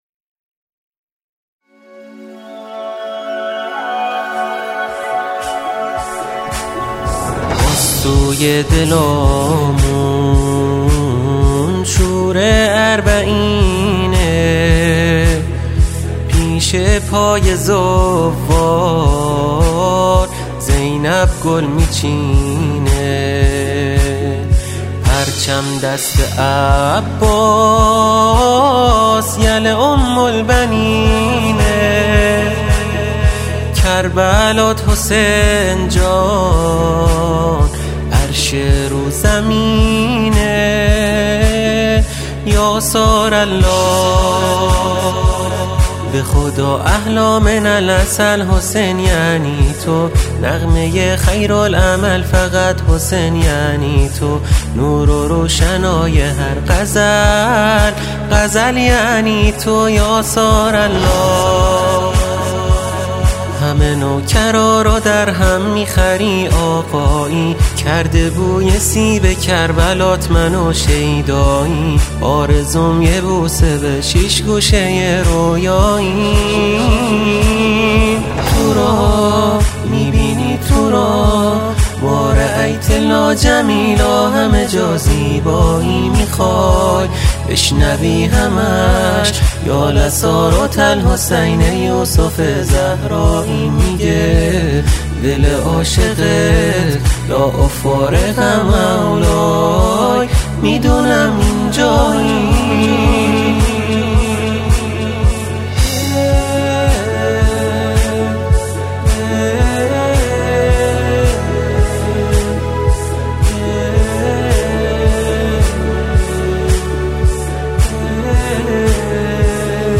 در این سرود او، شعری را با موضوع اربعین را می خواند.